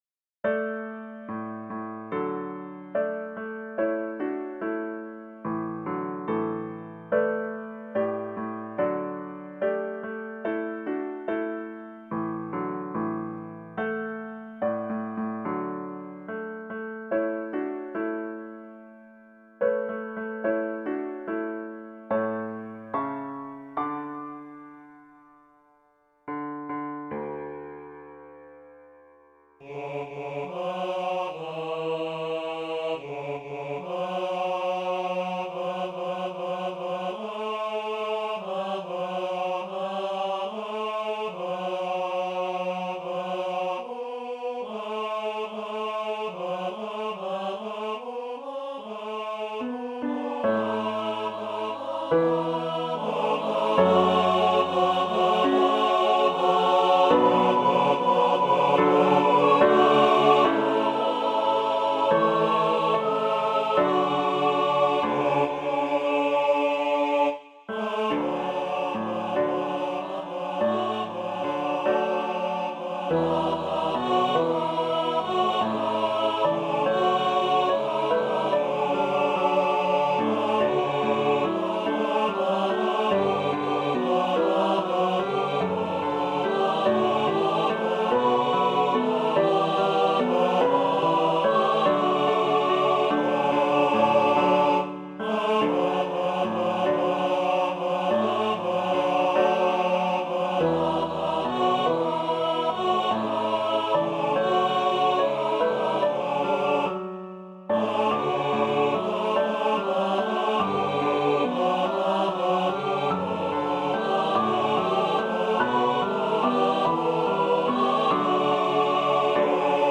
Voicing/Instrumentation: SATB
Choir with Soloist or Optional Soloist Includes Vocal Obbligato/Descant